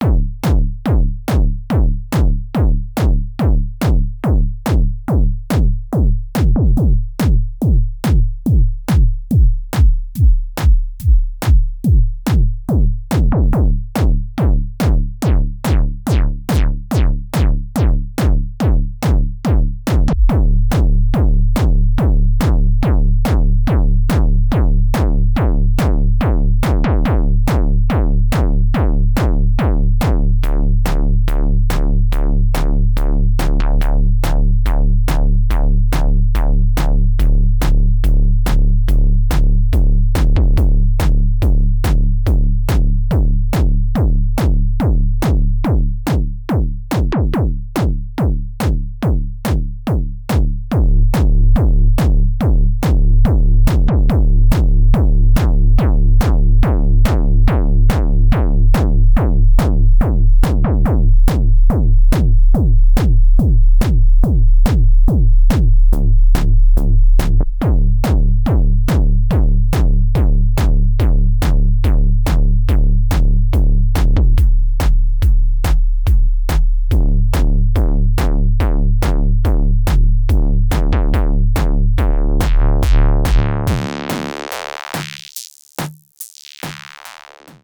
Kick flavours of Machinedrum’s BD2 :wink:
Like I said it depends on what kinds of kicks you like.